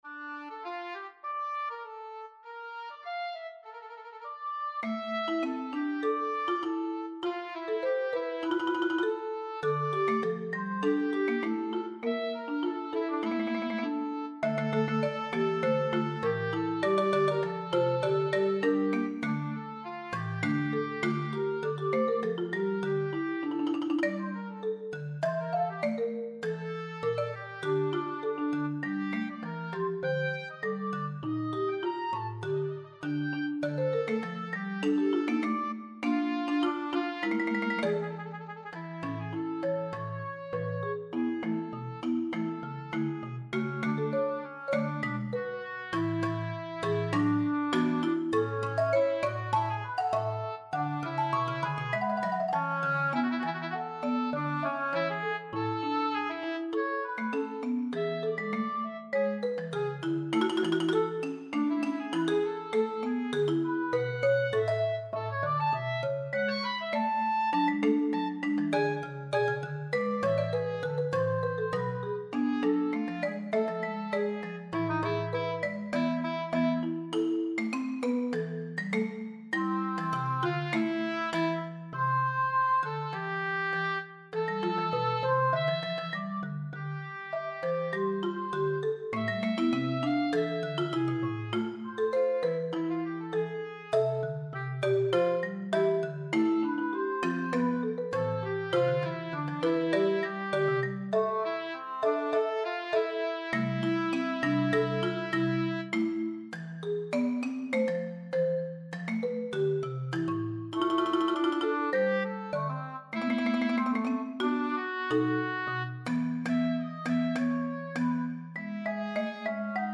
Fugue for three